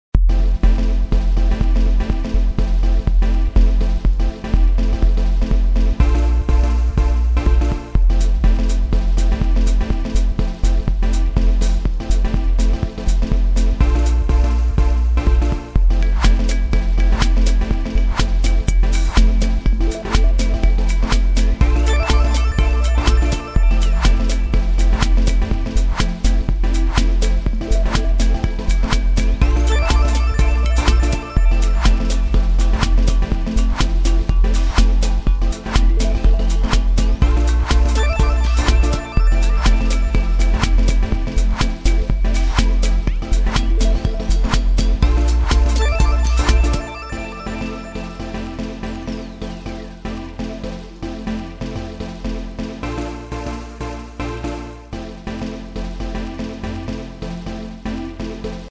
Fun and upbeat dance/house music for addictive intro